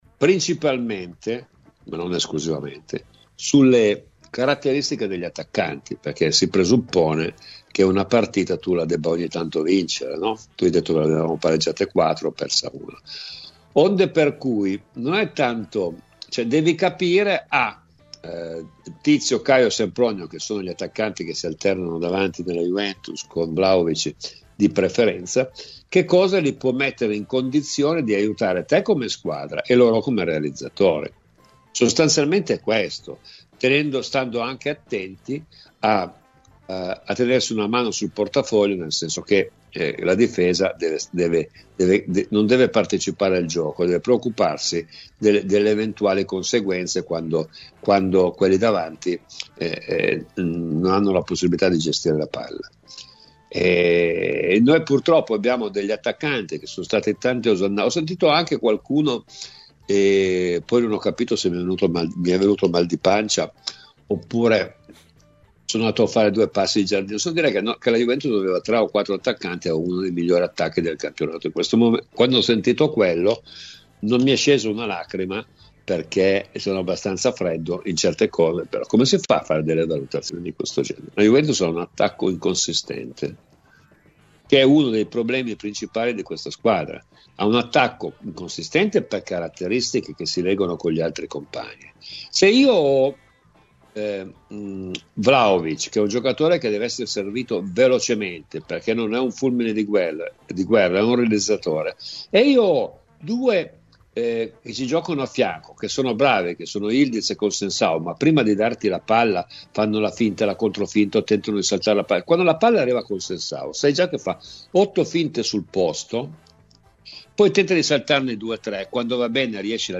Domenico Marocchino ha parlato di Juventus ai microfoni di Radio Bianconera: “Stavo pensando a quali potessero essere le soluzioni per far sì che la squadra sia un attimino più identificabile - le sue parole nel corso di “Cose di Calcio” - Ad esempio se Yildiz giocasse dietro la punta sarebbe molto più libero di fare quello che vuole, come fa Nico Paz nel Como.